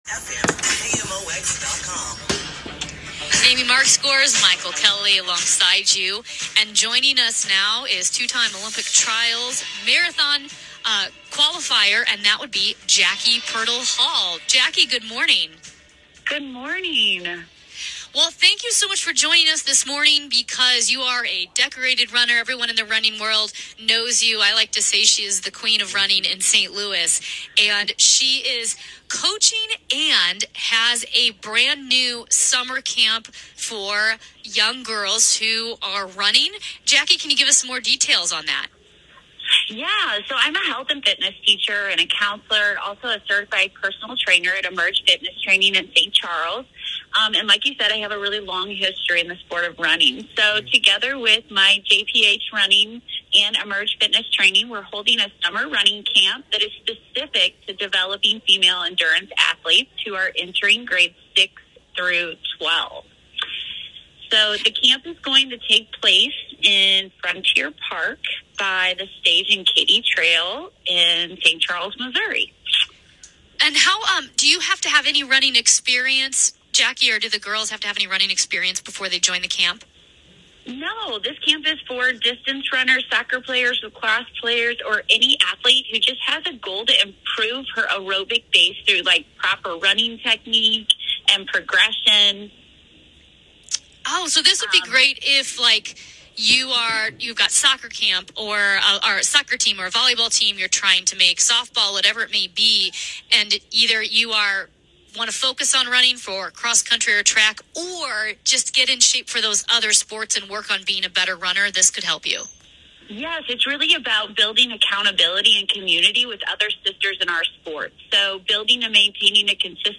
KMOX Interview